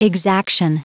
Prononciation du mot exaction en anglais (fichier audio)
Prononciation du mot : exaction